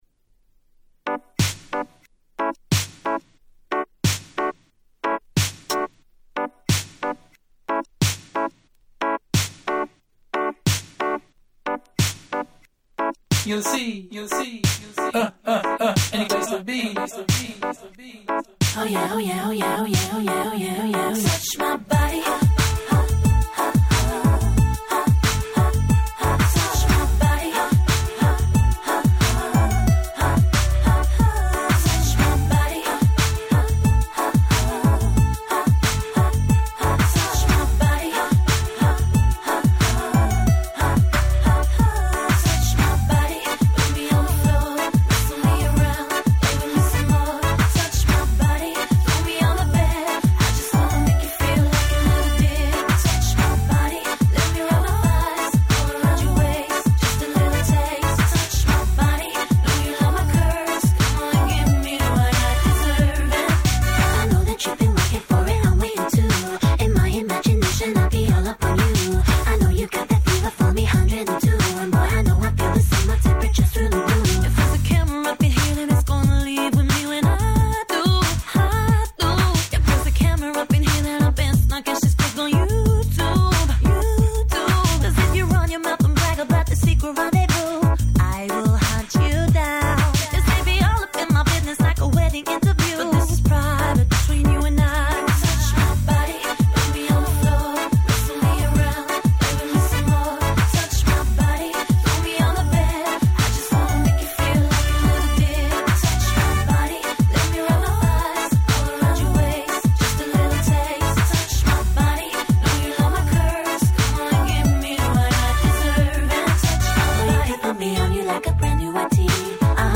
Lovers Reggae ラバーズレゲエ